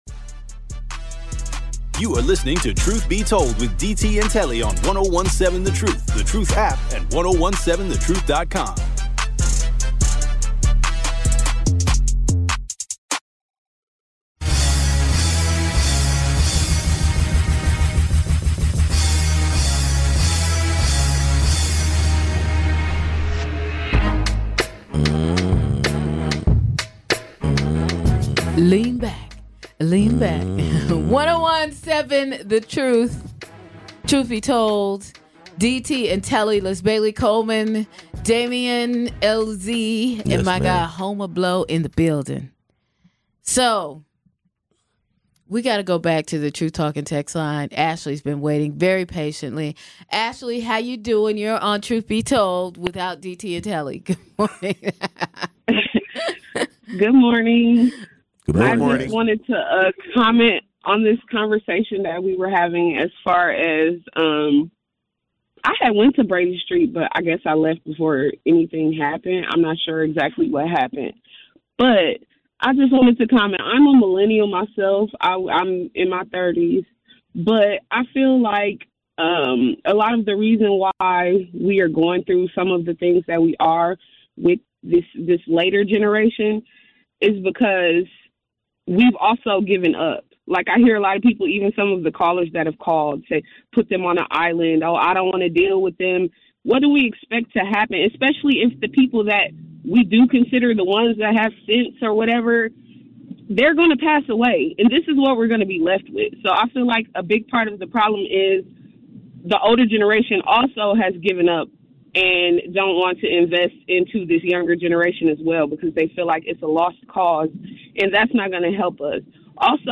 From unexpected encounters to unforgettable moments, the trio shares their firsthand experiences and reflections on the chaos. With humor and insight, they dissect what happened, the stories behind the mayhem, and how it all reflects the vibrant life of the community.